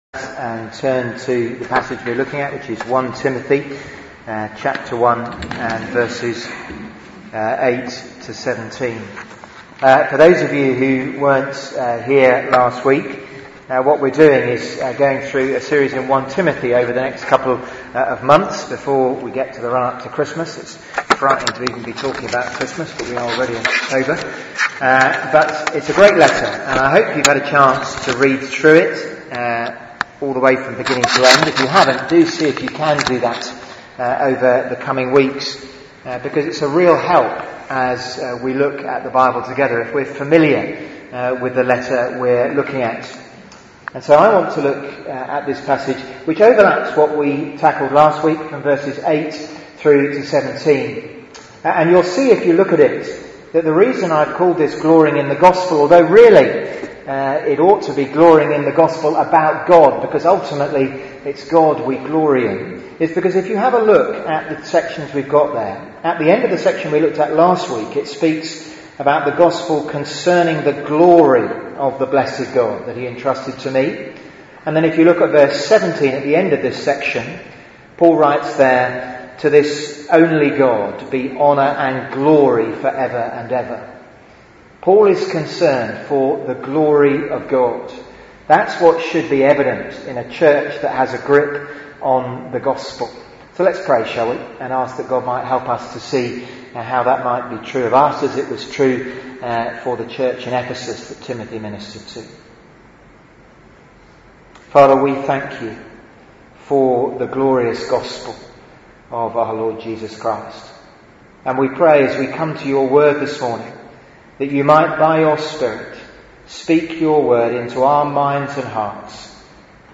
Theme: Glorifying in the Gospel Sermon